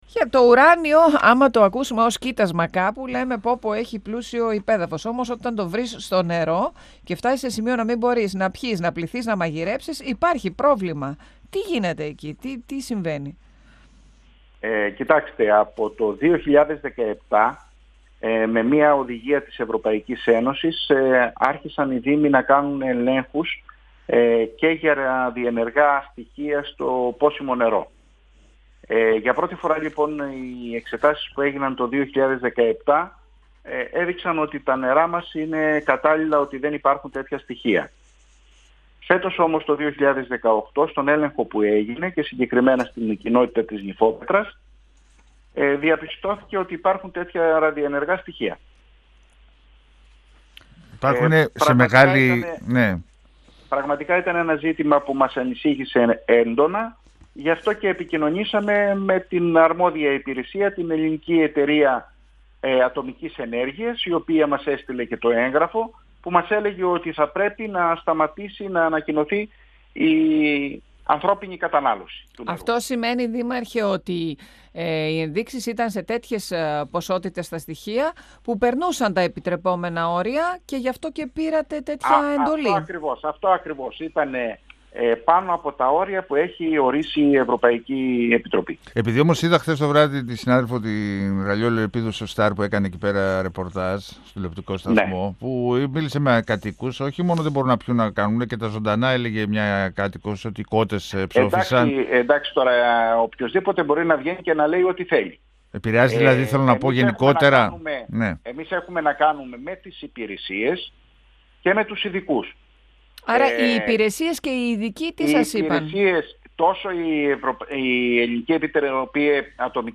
O δήμαρχος Βόλβης Διαμαντής Λιάμας,  στον 102FM του Ρ.Σ.Μ. της ΕΡΤ3